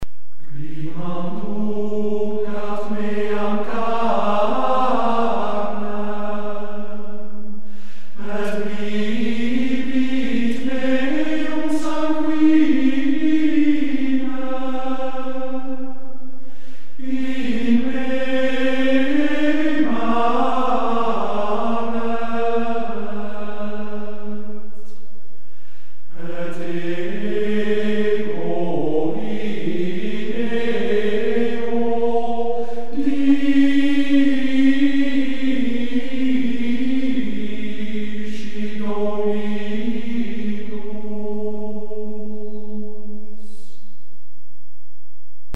Gregoriaanse gezangen uit de
De koorleden bij de opname in Gent
Gregoriaans Abdijkoor Grimbergen